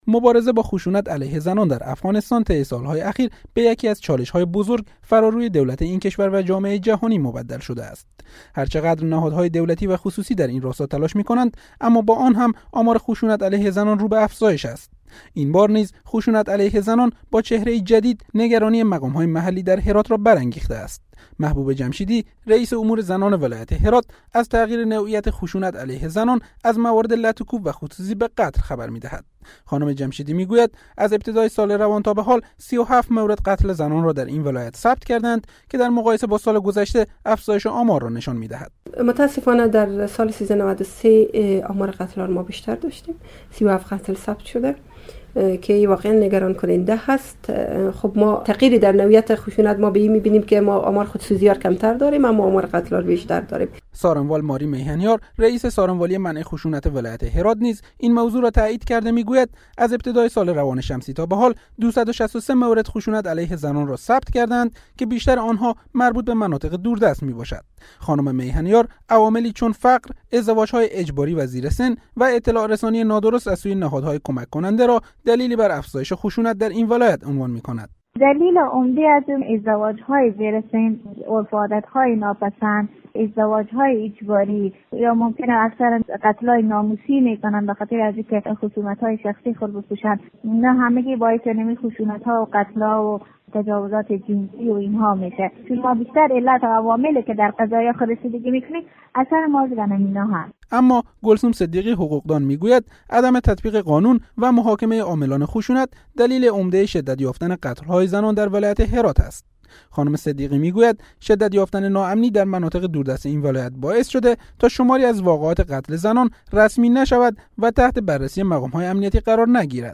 گزارش رادیویی را در این مورد از اینجا بشنوید